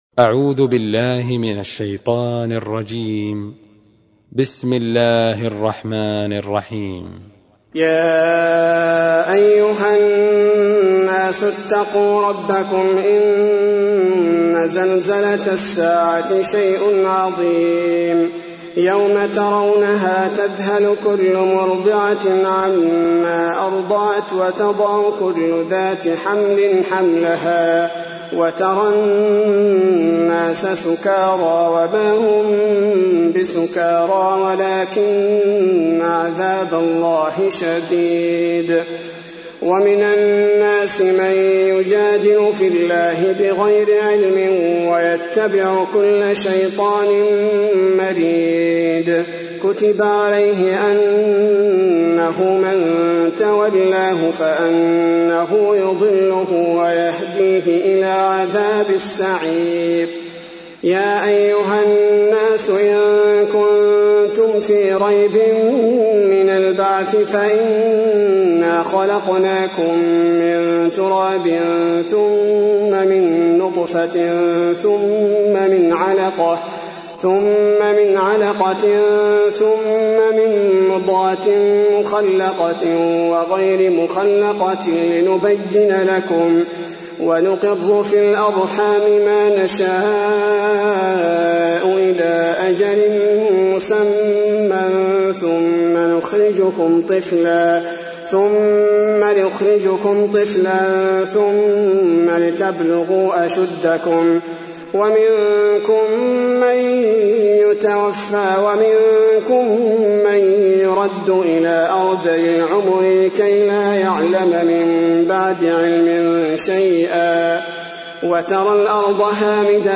22. سورة الحج / القارئ